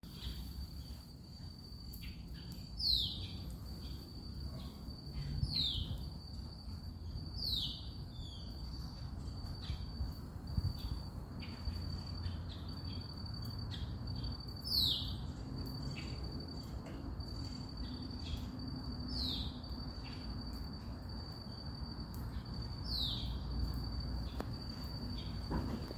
Screaming Cowbird (Molothrus rufoaxillaris)
Country: Argentina
Location or protected area: San Miguel, capital
Condition: Wild
Certainty: Recorded vocal